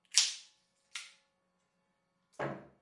描述：由Tascam HDP2和Rode NT4录制。
Tag: 解锁 解锁 锁定 钥匙